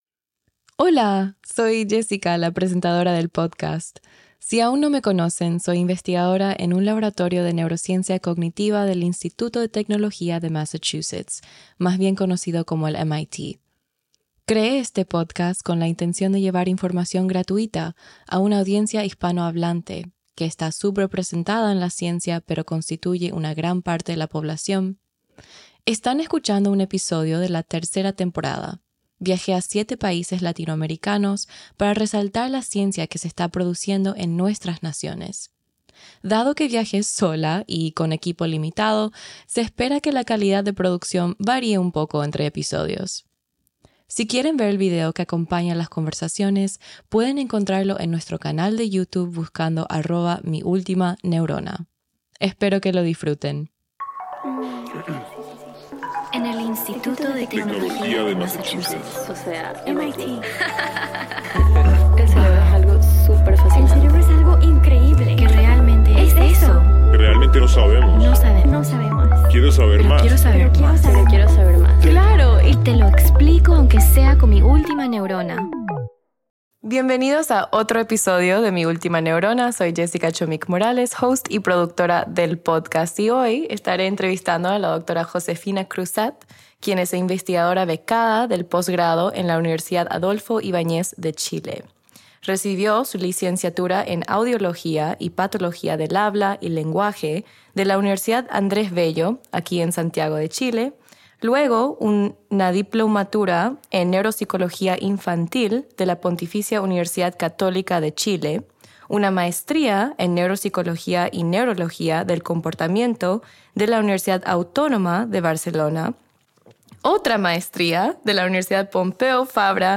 La entrevista aborda la interrelación entre lenguaje, funciones cognitivas y plasticidad cerebral, así como su transición hacia la neurociencia computacional. Además, se discuten temas como la investigación en enfermedades neurodegenerativas, el papel de mentores, y la representación latinoamericana en la ciencia y los sesgos en inteligencia artificial.